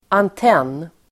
Uttal: [ant'en:]